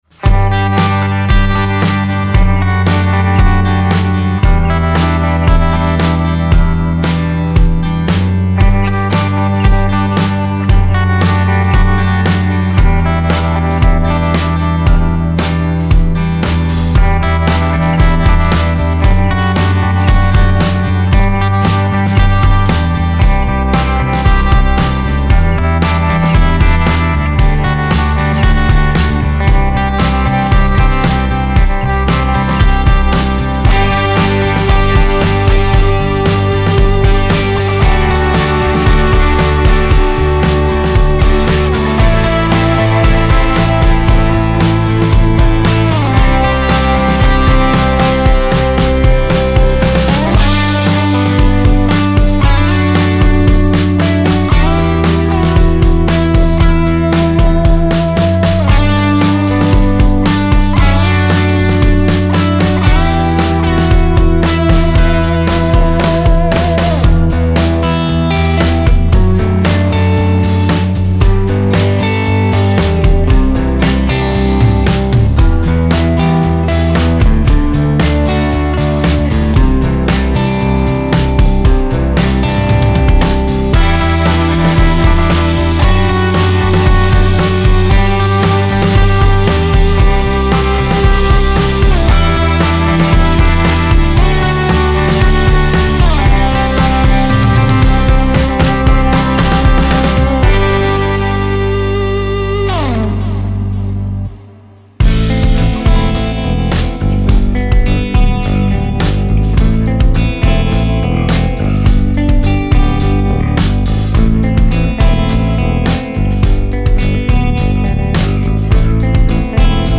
Genre:Indie Pop
553 Guitar Loops Live Performed & Played